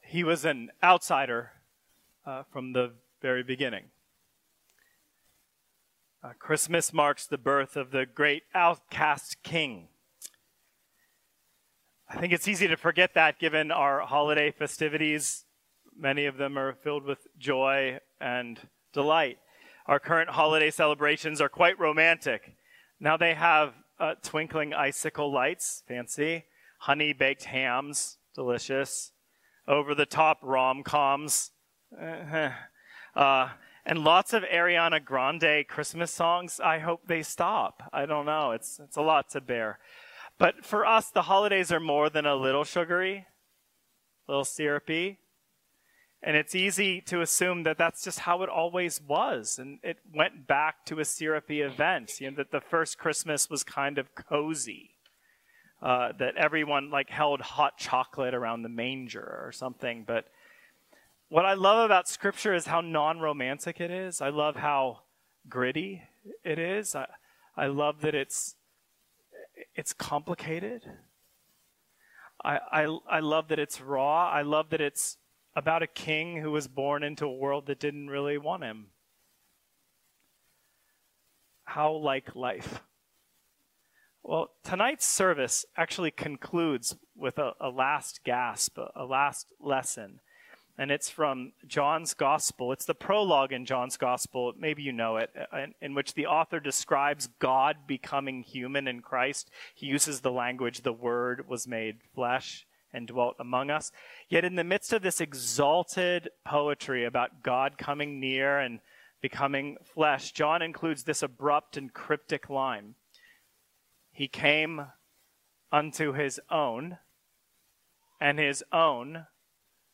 When Christmas is Very Gray -A Christmas Eve Homily